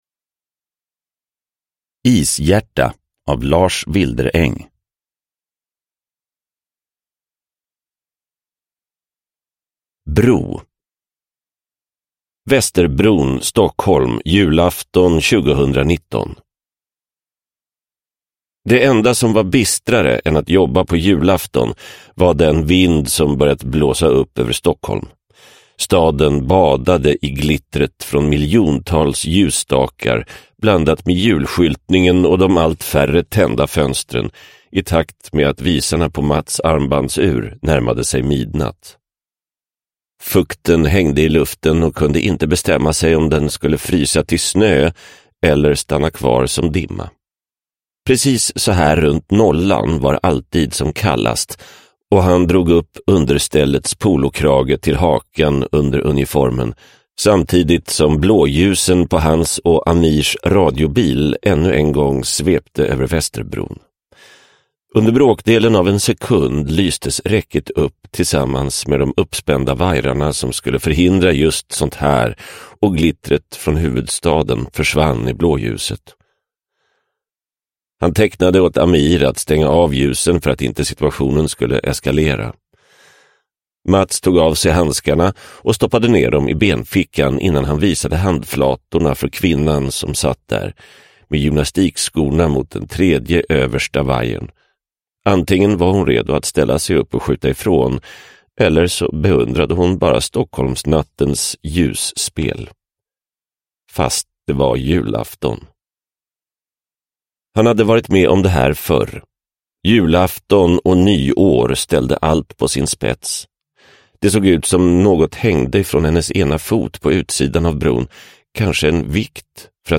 Ishjärta – Ljudbok – Laddas ner